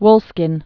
(wlskĭn)